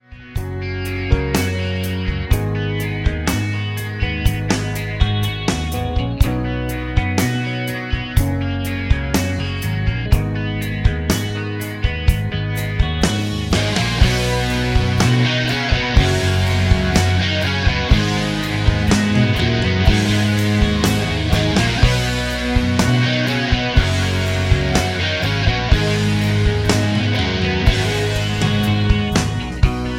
C#
MPEG 1 Layer 3 (Stereo)
Backing track Karaoke
Rock, Country, 2000s